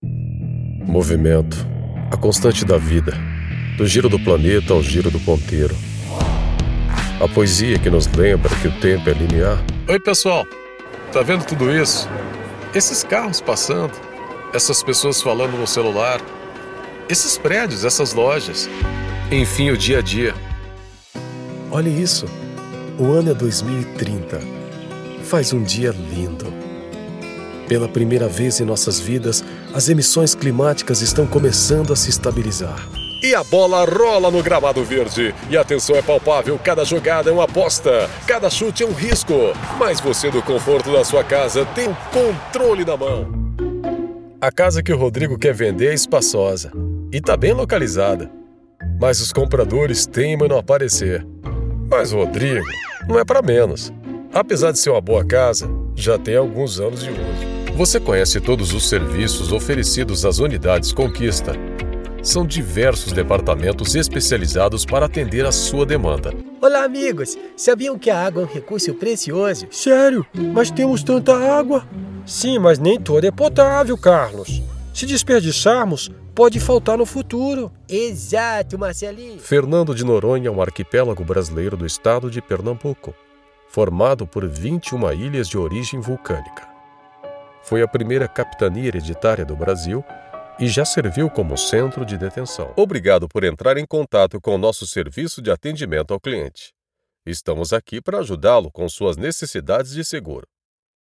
Brazilian voiceover, portuguese voiceover, brazilian voice actor, brazilian voice talent, brazilian portuguese voiceover, brazilian portuguese voice talent, brazilian portuguese voice actor, brazilian male voiceover, portuguese male voiceover, brazilian elearning voice, brazilian voice artist, brazilian portuguese
Sprechprobe: Werbung (Muttersprache):